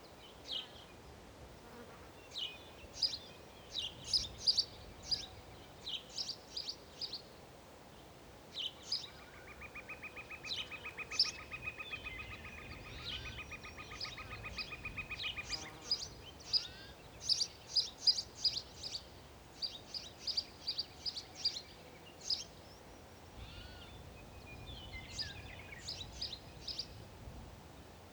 Som dos pássaros
mixkit-forest-with-birds-and-insect-flying-hum-1223.wav